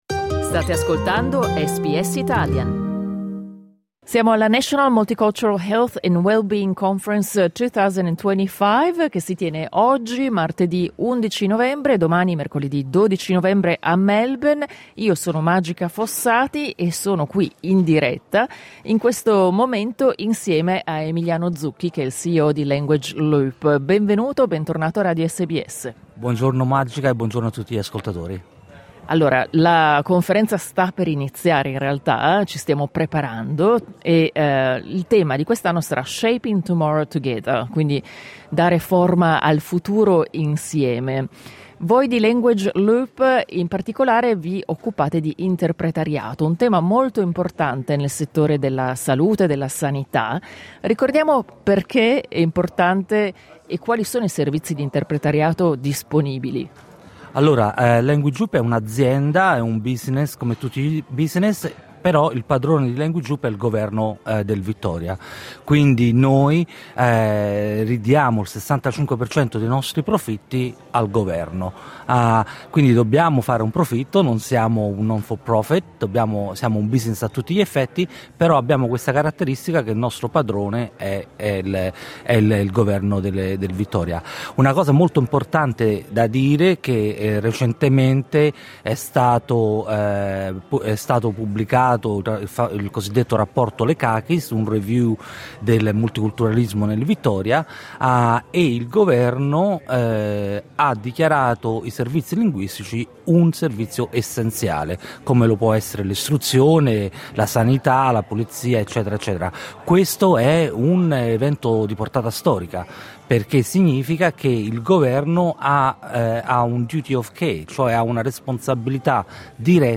La National Multicultural Health and Wellbeing Conference 2025 si tiene tra martedì 11 e mercoledì 12 novembre a Melbourne. Nel corso della prima giornata eravamo presenti anche noi di SBS, media partner ufficiale dell'evento, e abbiamo intervistato alcuni degli ospiti italofoni.
Clicca sul tasto "play" in alto per ascoltare l'intervista integrale